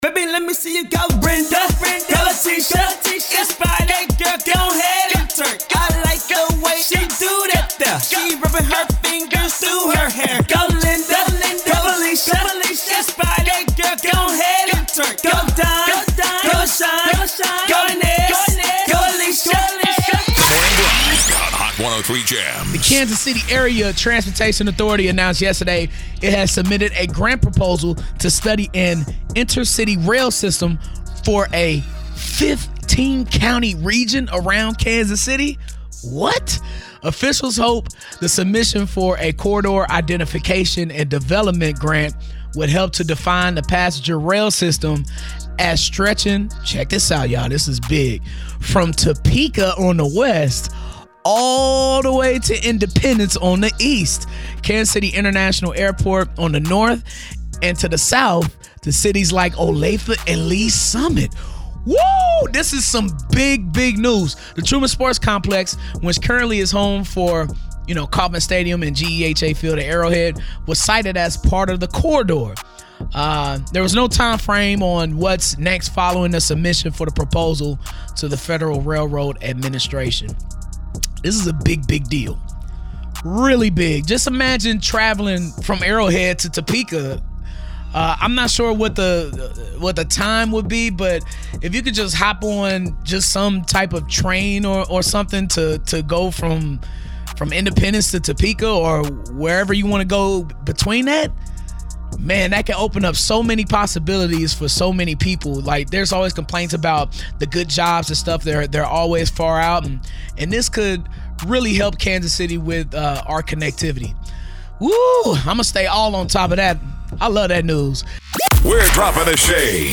- Comedian BILL BELLAMY called into the show to discuss his legacy ahead of his stop at the IMPROV